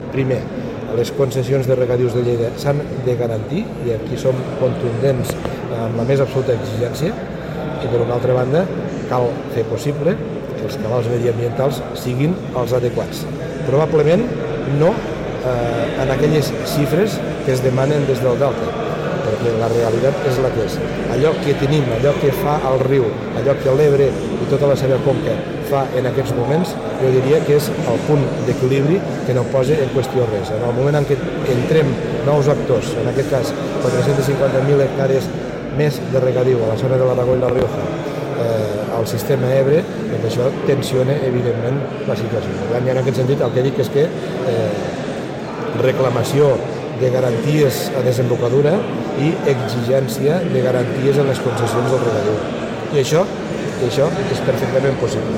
El president de la Diputació de Lleida ha fet aquestes declaracions a la 15a Jornada Reg i Futur organitzada per la Comunitat General de Regants del Canal d’ Urgell al Teatre l’Amistat de Mollerussa